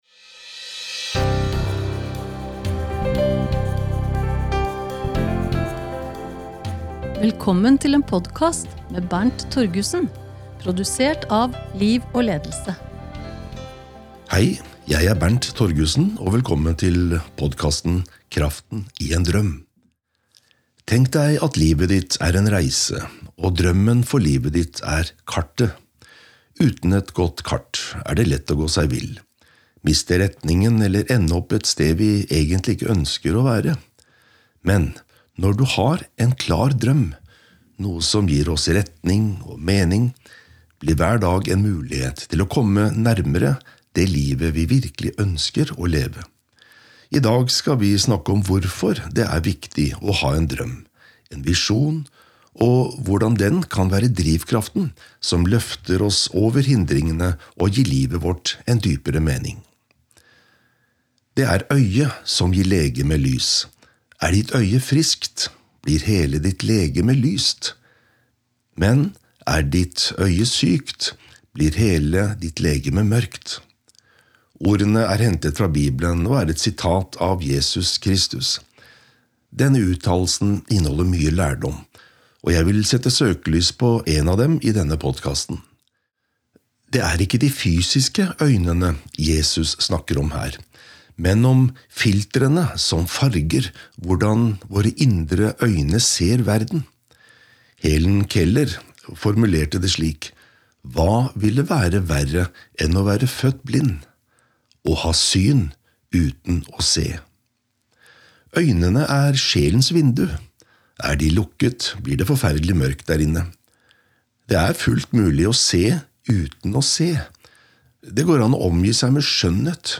Lydbok: Kraften i en drøm